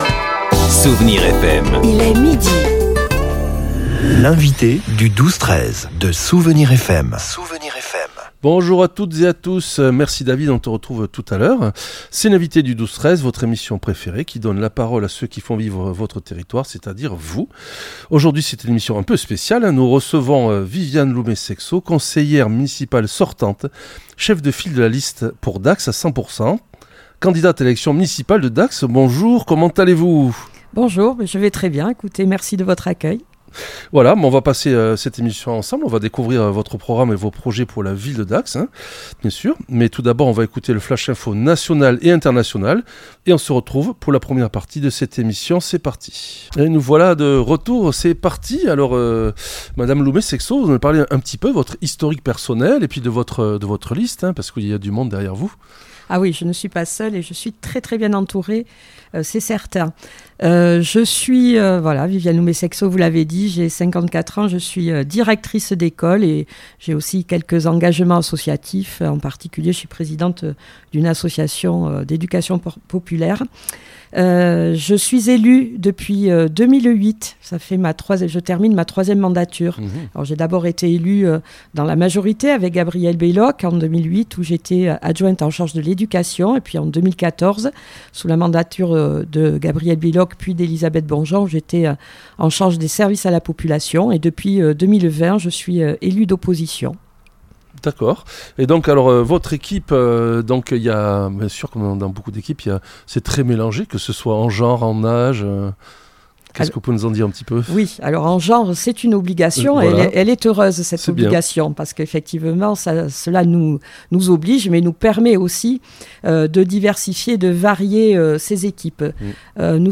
L’invité(e) du 12-13 recevait aujourd’hui Viviane Loumé-Seixo, conseillère municipale sortante et cheffe de file de la liste « Pour Dax à 100 % !